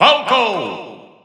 The announcer saying Falco's name in English and Japanese releases of Super Smash Bros. 4 and Super Smash Bros. Ultimate.
Falco_English_Announcer_SSB4-SSBU.wav